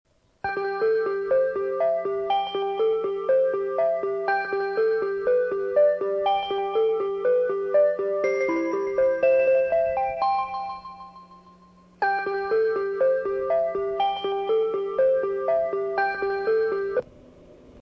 スピーカー：UNI-PEX LH-15T
音質：D
３番線接近メロディー（せせらぎ） 上り・岩国方面 (87KB/17秒)
山陽本線必殺頭切れです…
曲は上りが“せせらぎ”、下りが“春”で統一されています。